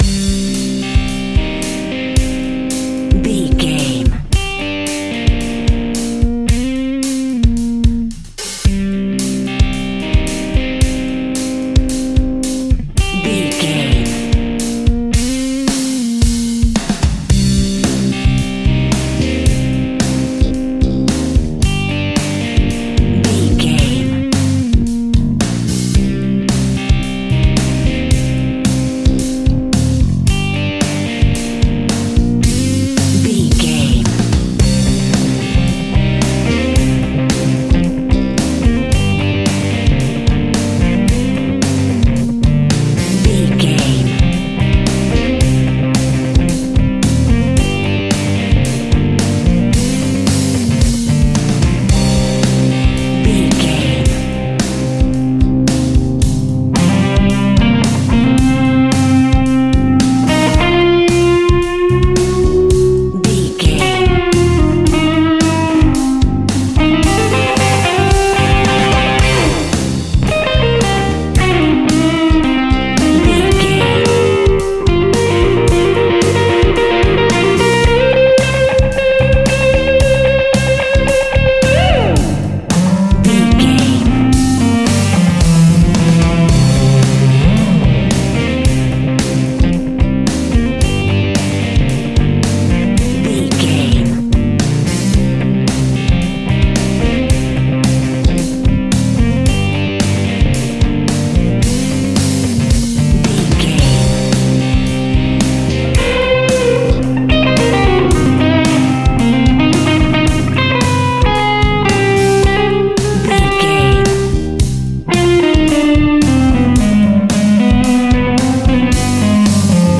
Aeolian/Minor
melancholic
energetic
uplifting
electric guitar
bass guitar
drums
hard rock
blues rock
instrumentals
heavy drums
distorted guitars
hammond organ